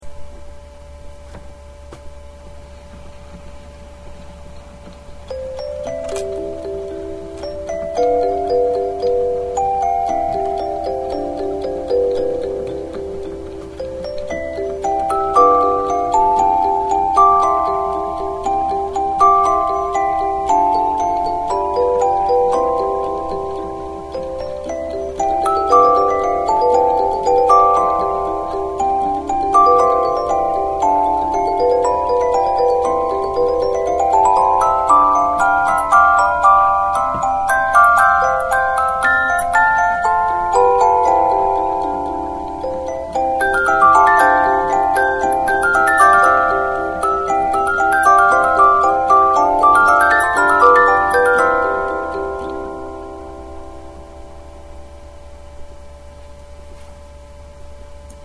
実物オルゴールでの生録音は
ドイツ民謡
メロディがきれいなので意外にオルゴールにも向いているかもしれませんね。